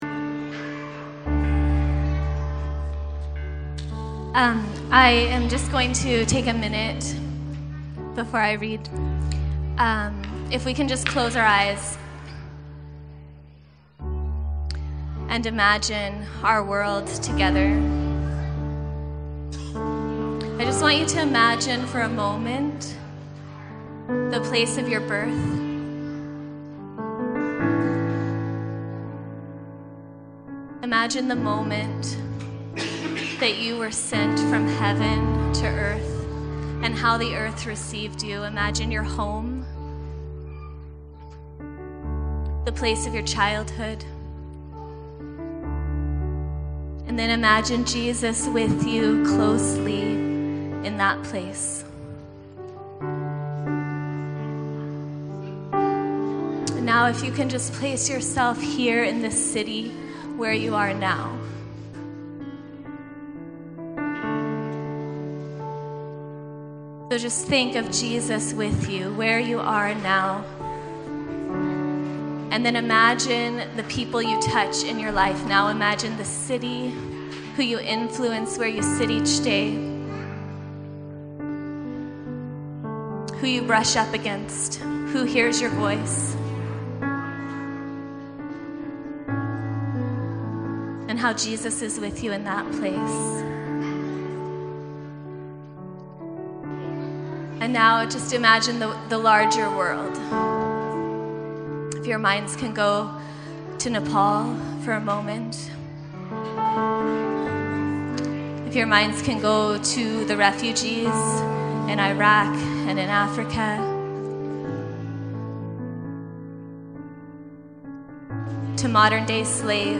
This Jesus – a poem during Celebration Sunday